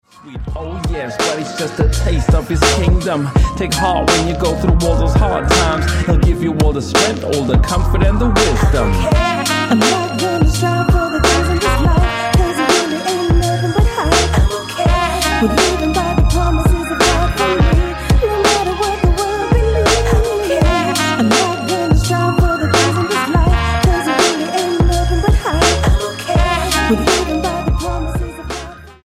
London-based rapper
Style: Hip-Hop